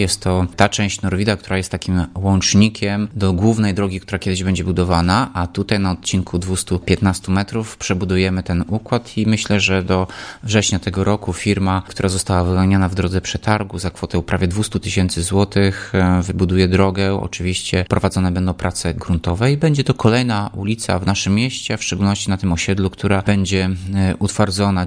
– Nowa nawierzchnia ulicy Norwida będzie gotowa za około trzy miesiące – mówi Tomasz Andrukiewicz, prezydent Ełku.